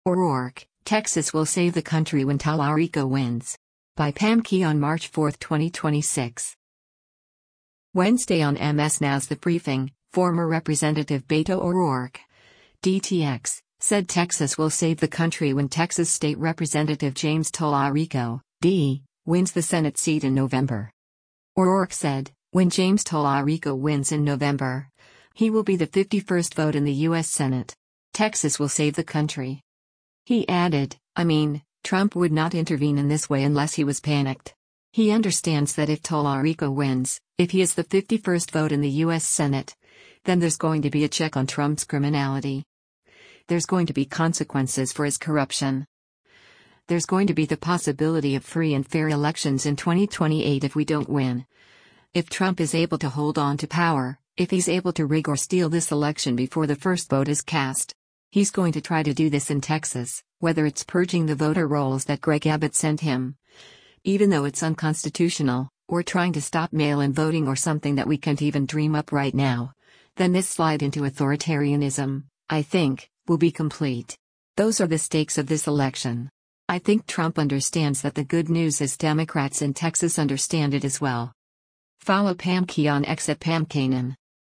Wednesday on MS NOW’s “The Briefing,” former Rep. Beto O’Rourke (D-TX) said “Texas will save the country” when Texas State Representative James Talarico (D) wins the Senate seat in November.